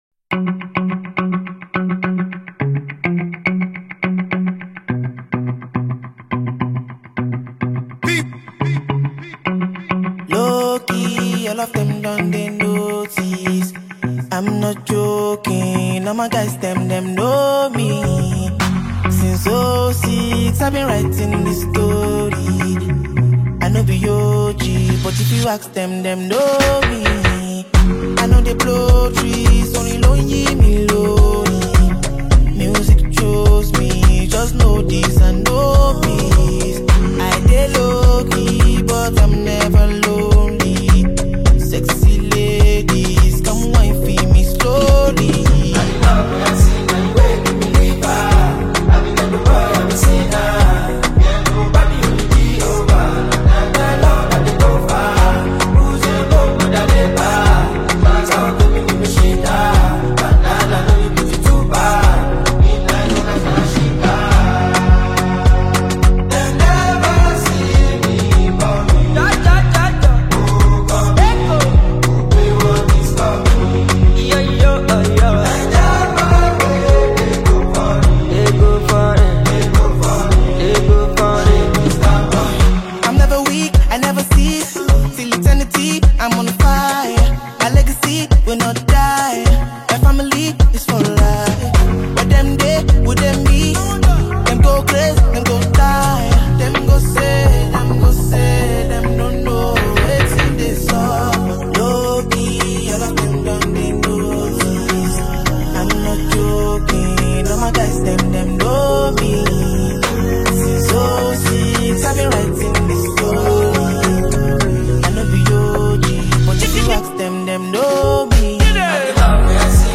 smooth vocals and the catchy hook
blends Afrobeat with contemporary sounds
With its upbeat tempo and catchy sounds